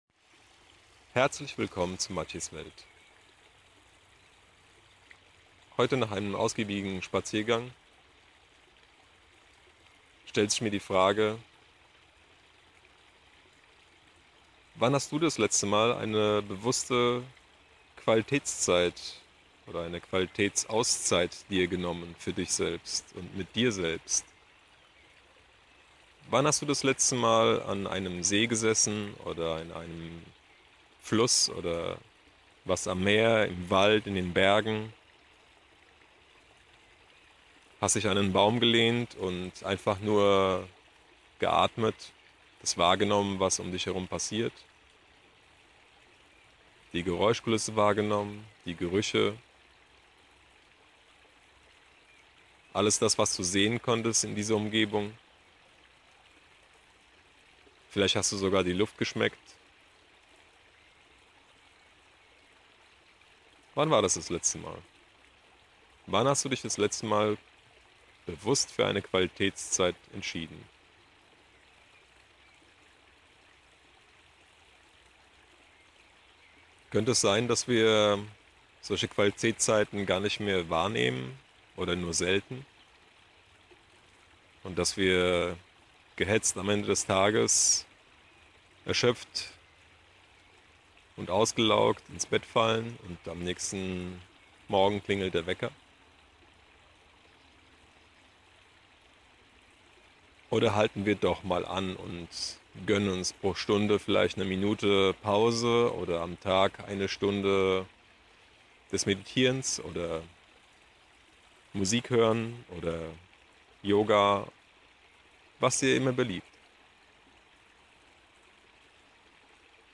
grüsse vom bächlein ;)